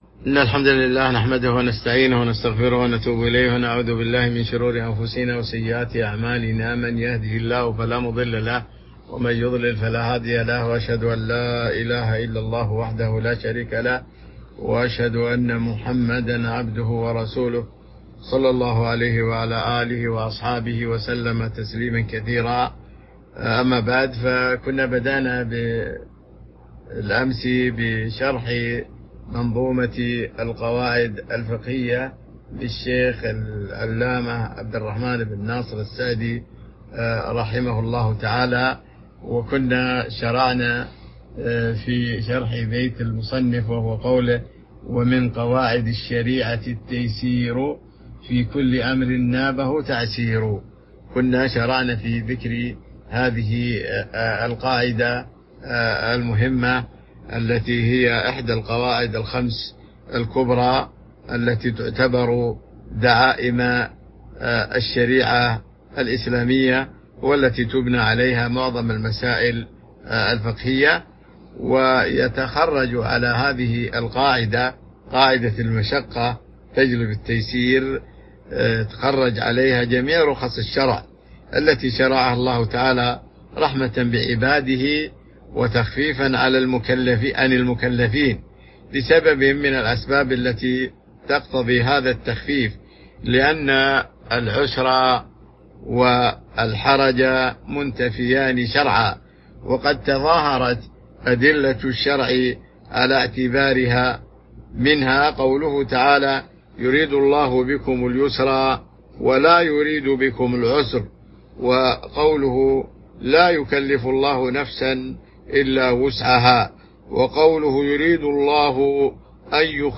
تاريخ النشر ٢٩ شوال ١٤٤٢ هـ المكان: المسجد النبوي الشيخ